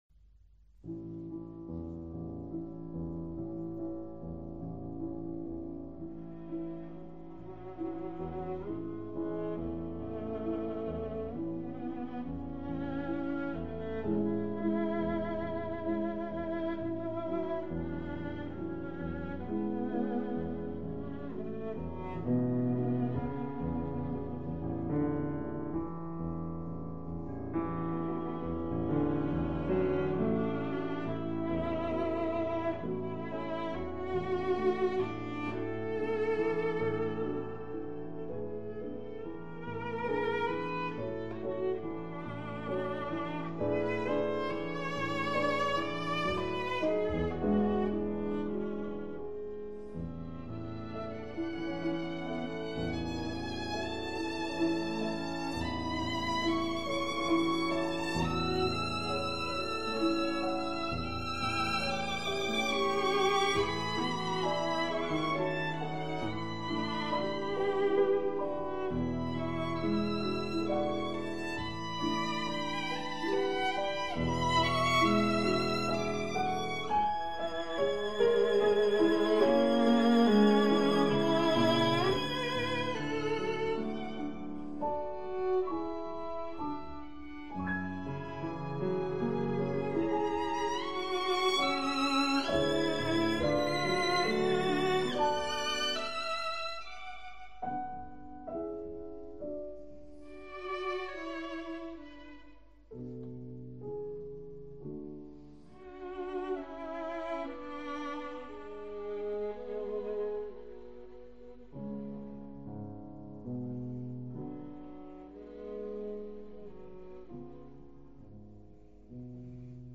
The part-writing is very nicely handled for each instrument.